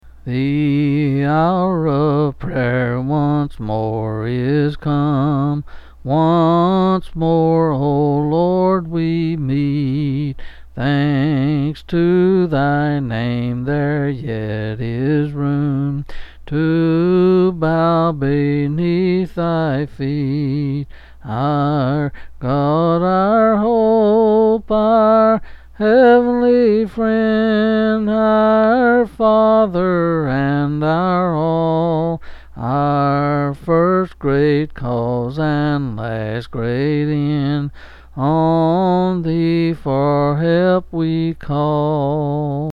Quill Selected Hymn